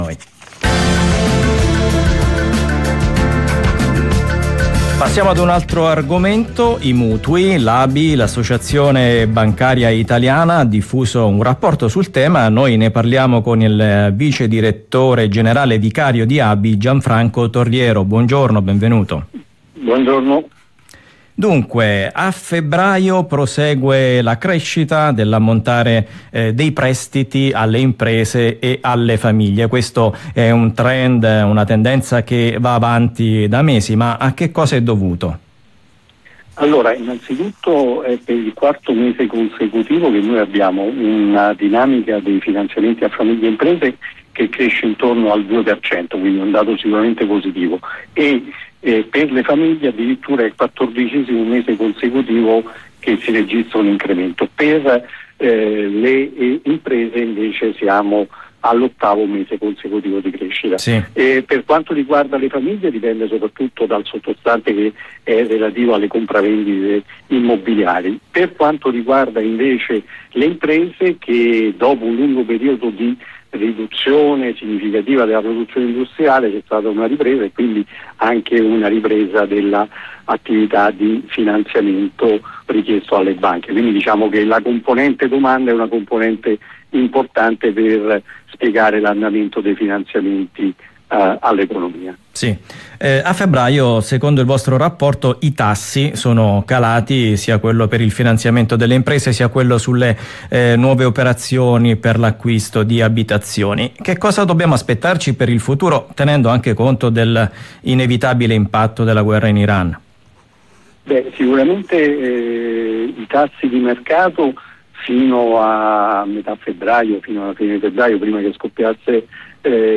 Intervista su Rai RadioUno